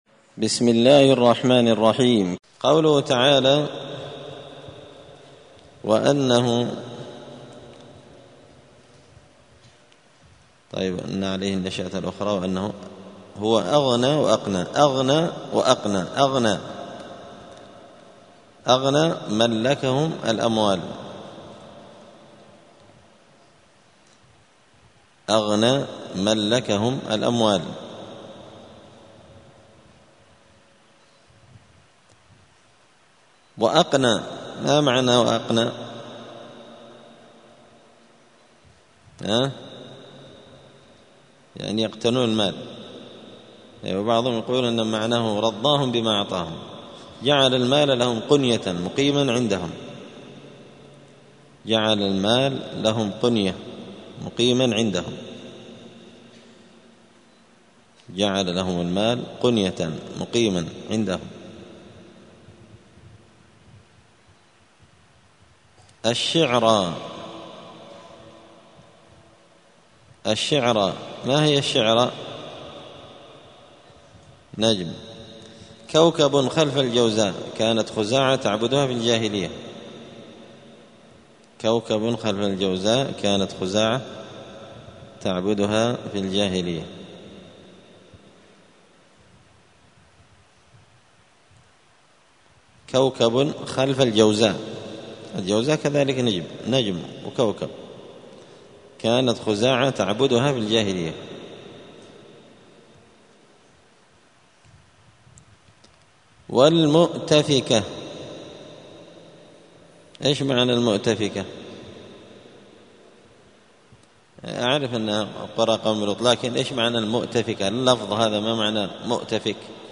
الأربعاء 4 محرم 1446 هــــ | الدروس، دروس القران وعلومة، زبدة الأقوال في غريب كلام المتعال | شارك بتعليقك | 21 المشاهدات